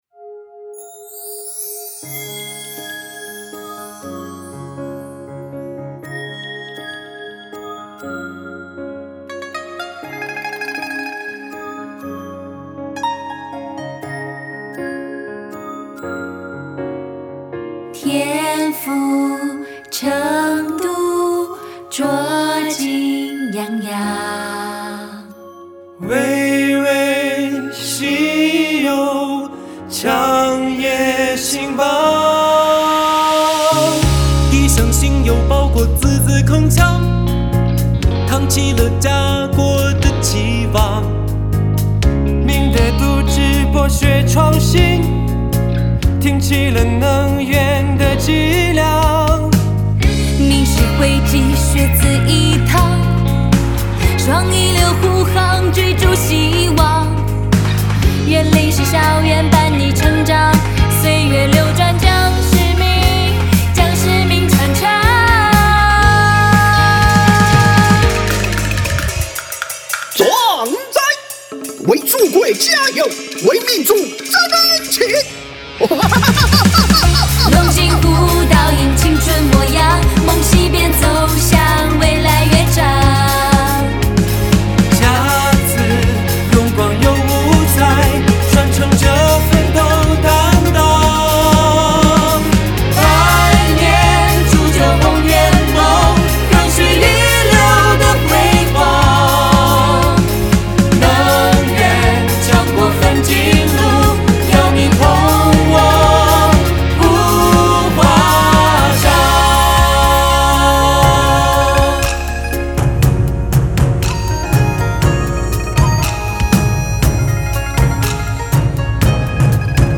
本科招生宣传单曲